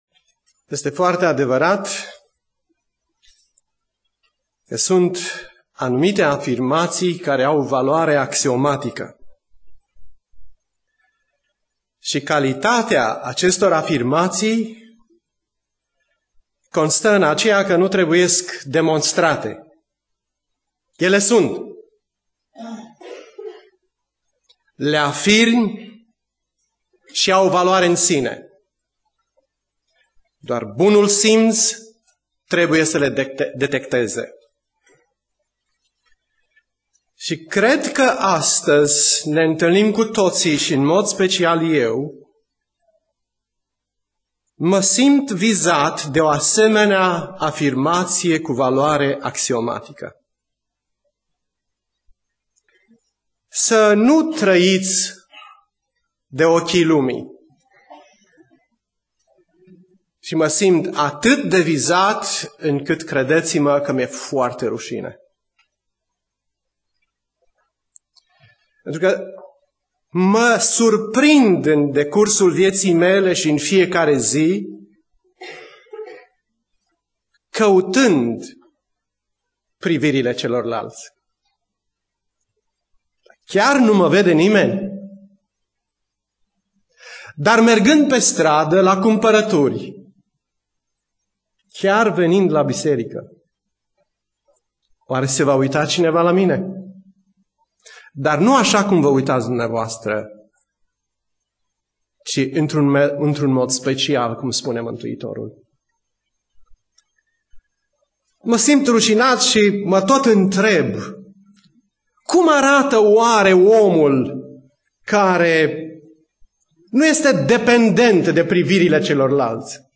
Predica Aplicatie - Matei 6